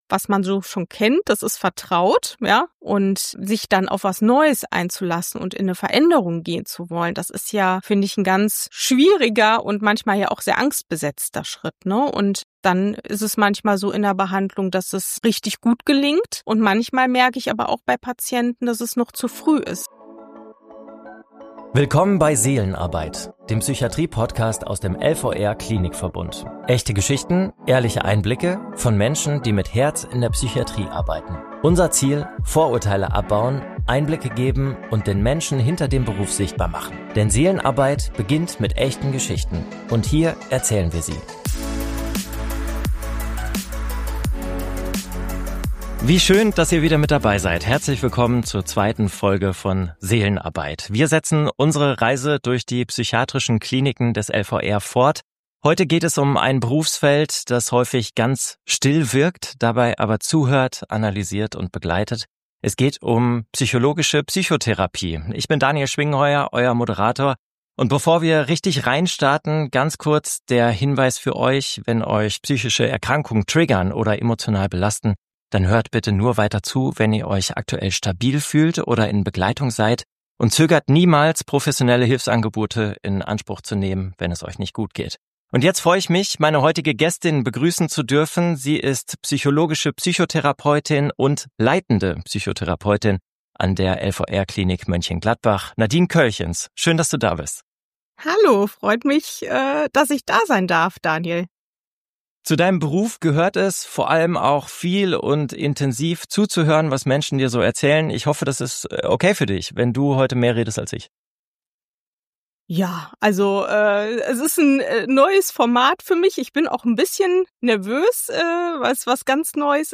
Offen und reflektiert spricht sie über die Zusammenarbeit mit Patient*innen – und macht dabei deutlich, wie bedeutend die psychische Gesundheit für uns alle ist.